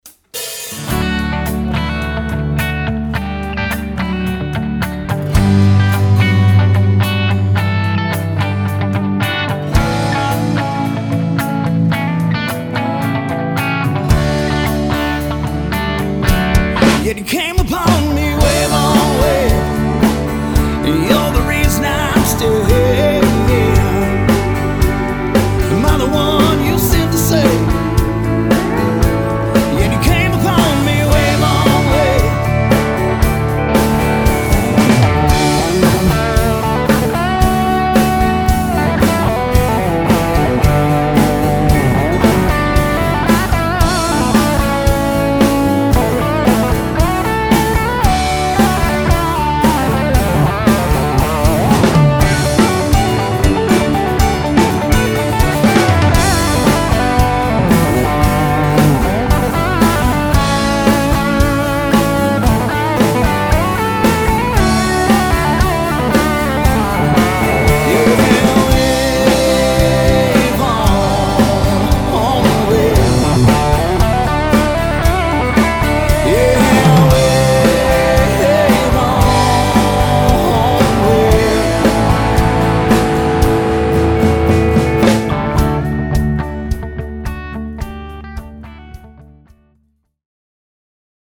Texas Country at it's best